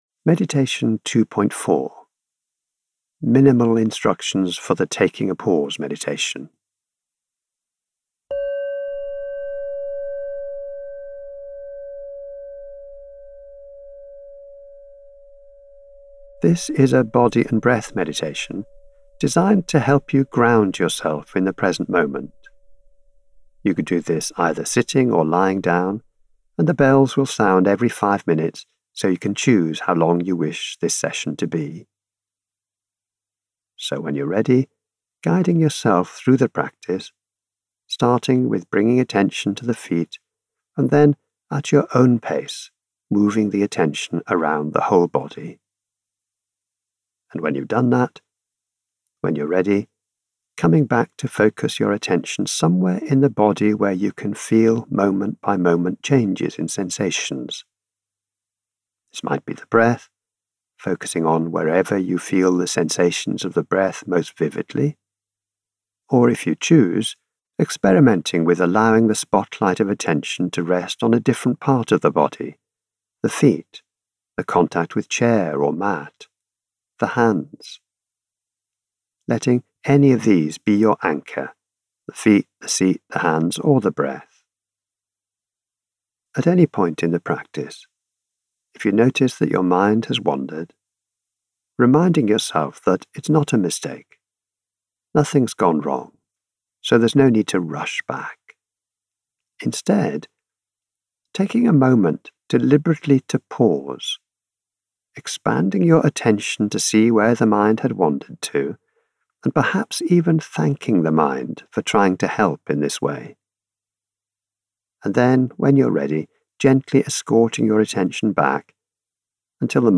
2.4_Minimal_instructions–Taking_a_Pause)Meditation.mp3